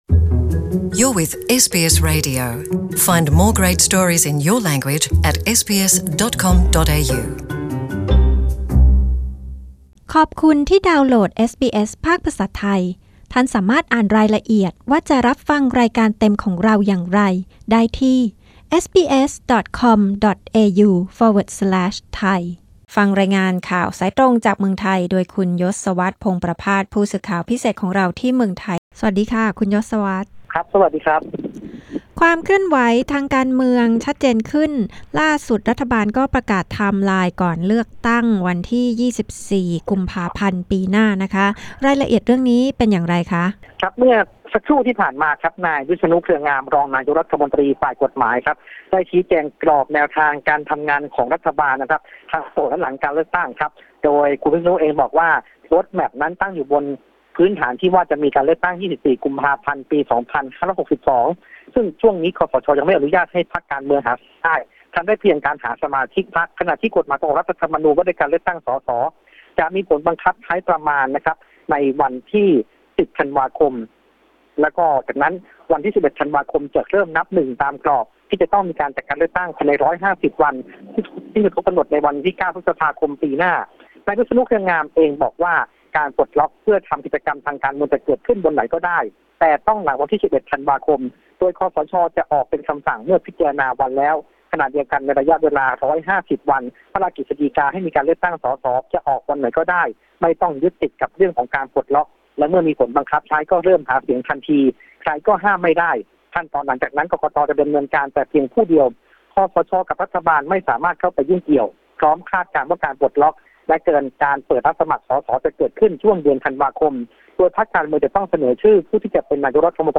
Thai news report Nov 8